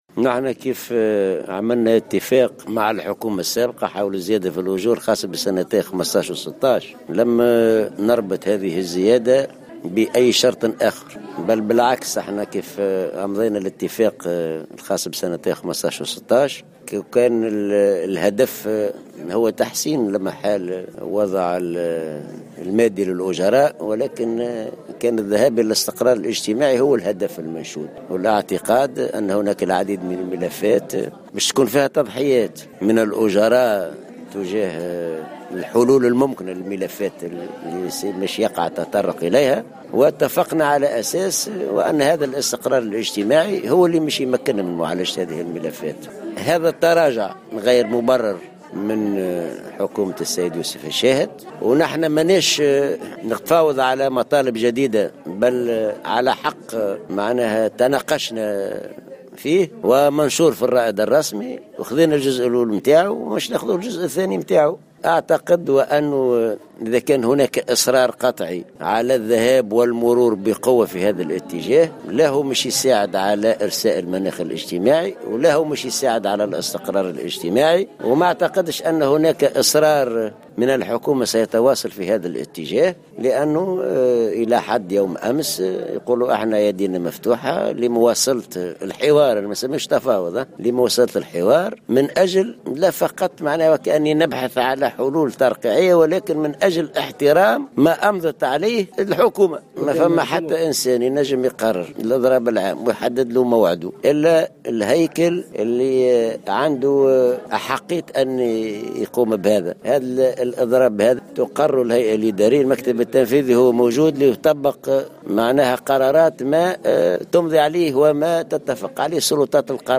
قال الأمين العام للاتحاد العام التونسي للشغل حسين العباسي خلال إشرافه اليوم الجمعة 18 نوفمبر 2016 على اختتام ندوة لقطاع التعليم الثانوي بسوسة إن تراجع حكومة يوسف الشاهد عن اتفاق الزيادات في الأجور لسنتي 2015 و2016 والذي تم إمضاؤه مع الحكومة السابقة غير مبرر.